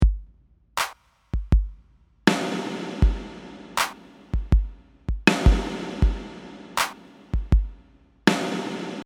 For the sake of consistency, I’ve sent our electronic loop to a separate bus with the same reverb, just to give it the same feeling of “space.”
Things are starting to drown in reverb, let’s start cleaning up.
loopreverb.mp3